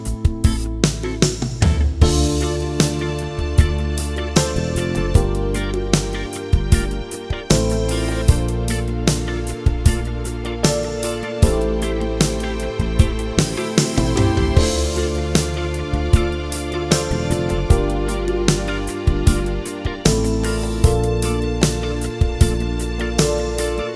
No Saxophone Solo Pop (1980s) 4:35 Buy £1.50